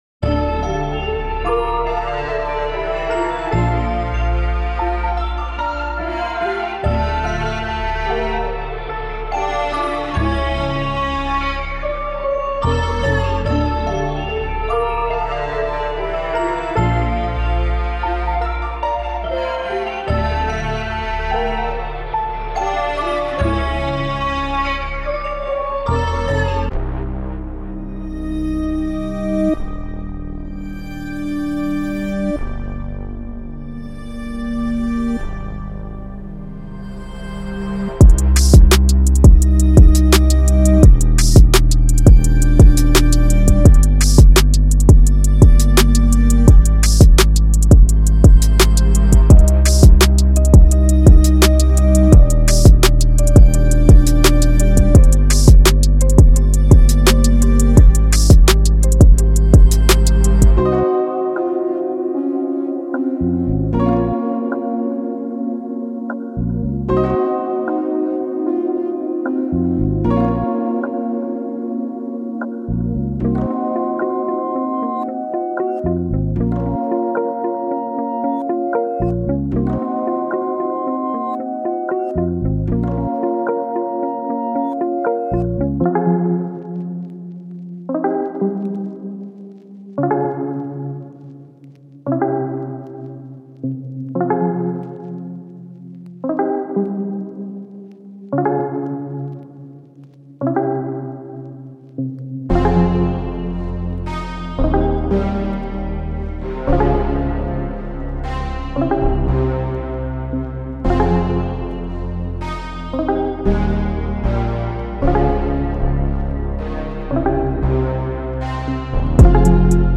STARSHIP GUITAR旋律 139
40 LUNAR VOXES  52
HYDRA 808的 18